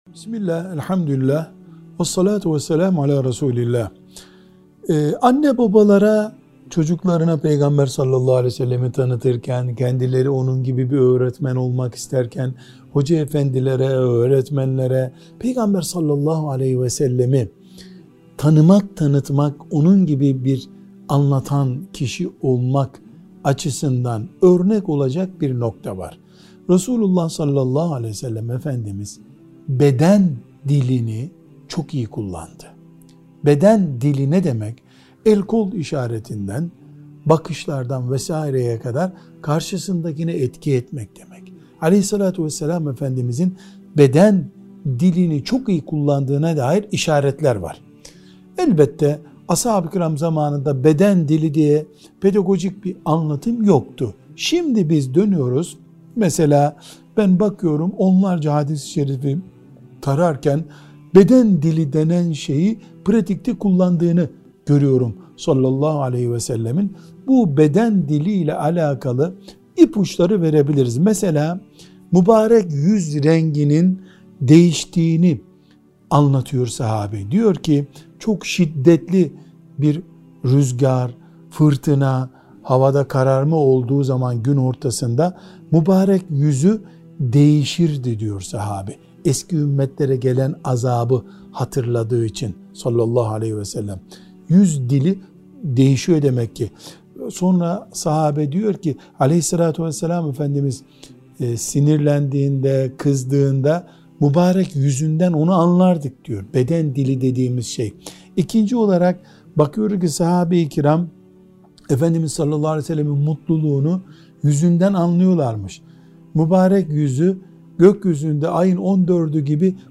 1. Sohbet Arşivi